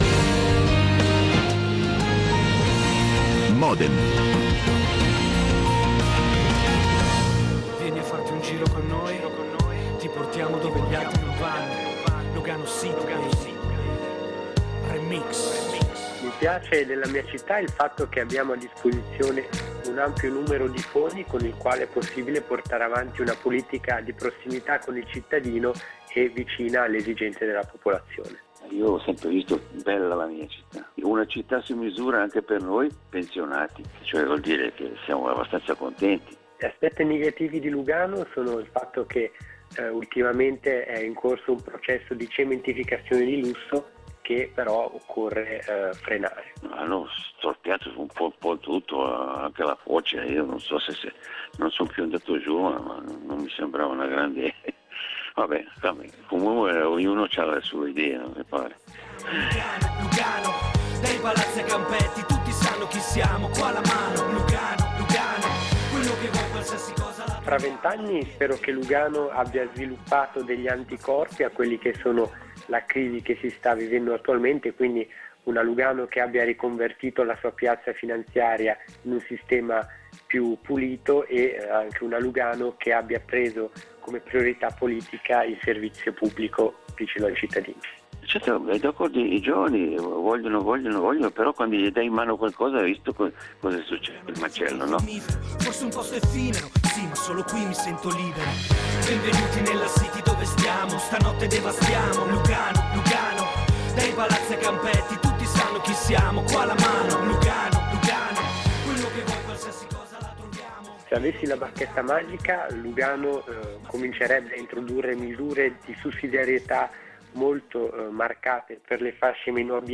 Dibattito pre elettorale in diretta dal caffé Olimpia con 6 candidati al Municipio